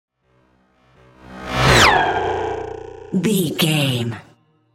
Sci fi vehicle whoosh
Sound Effects
dark
futuristic
intense
whoosh